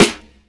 Boom-Bap Snare 68.wav